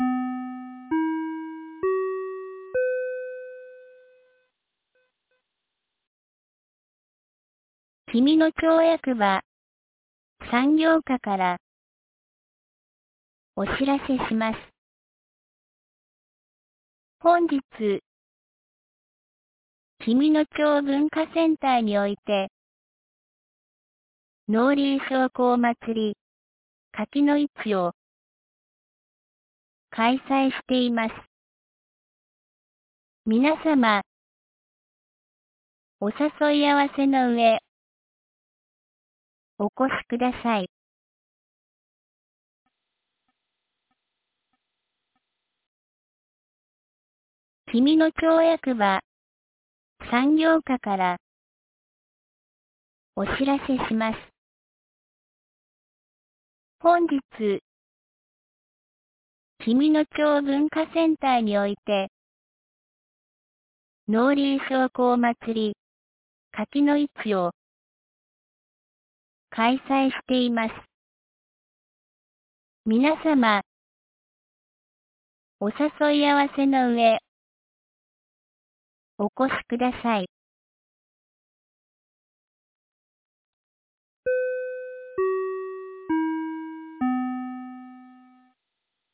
2023年11月26日 09時31分に、紀美野町より全地区へ放送がありました。